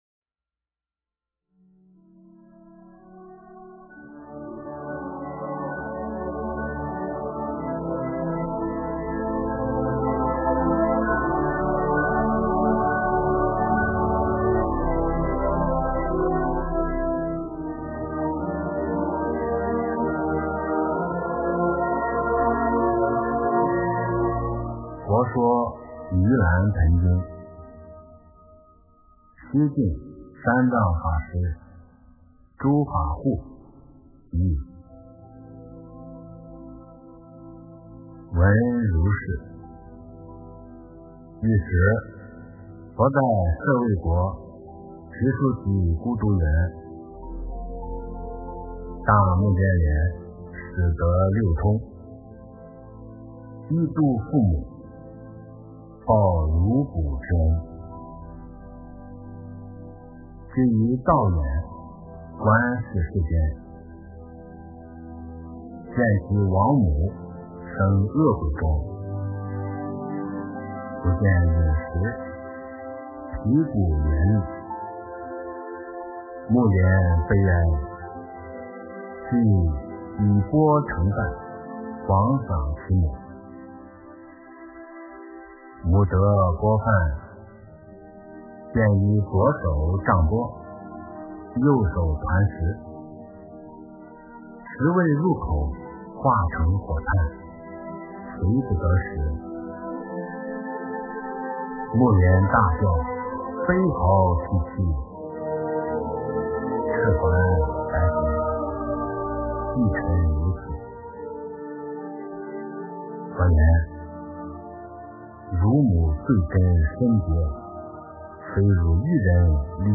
诵经
佛音 诵经 佛教音乐 返回列表 上一篇： 般若波罗密多心经.唱颂 下一篇： 法华经-五百弟子受记品 相关文章 17.